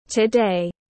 Ngày hôm nay tiếng anh gọi là today, phiên âm tiếng anh đọc là /təˈdeɪ/